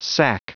Prononciation du mot sack en anglais (fichier audio)
Prononciation du mot : sack